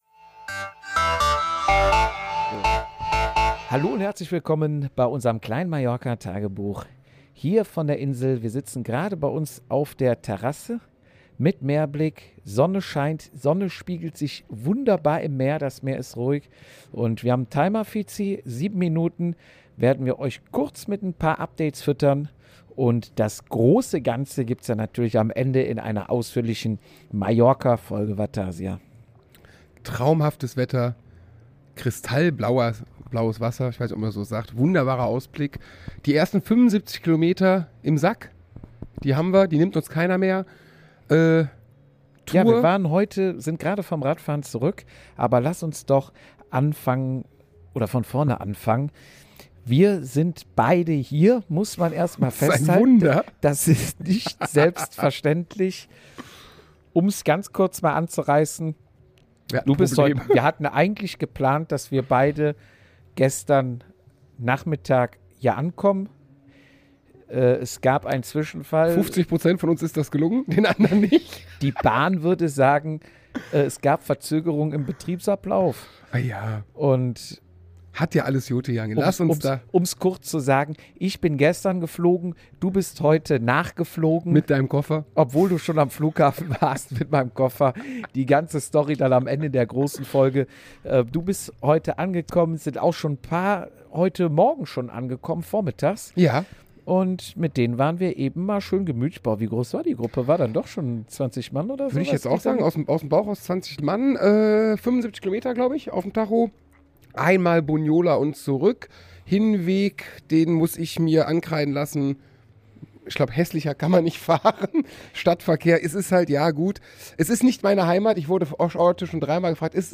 Wir nehmen euch mit auf unsere sonnige Terrasse auf Mallorca und